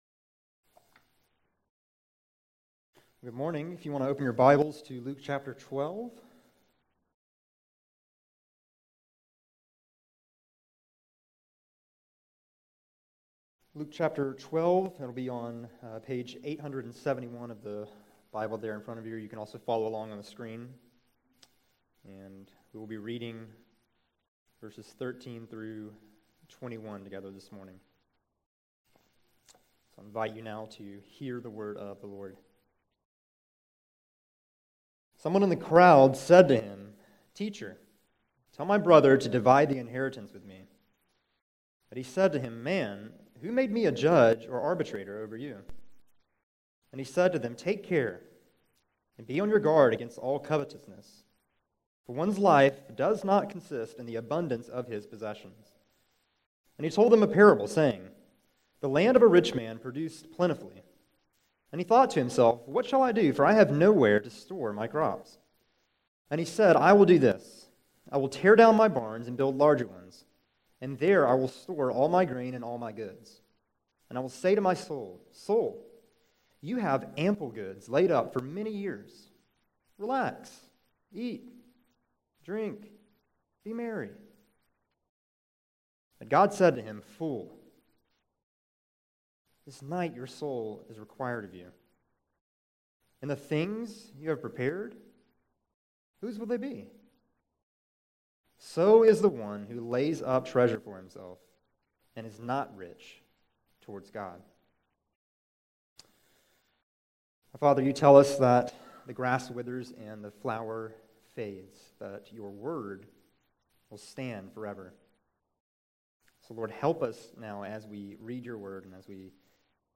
August 13, 2017 Morning Worship | Vine Street Baptist Church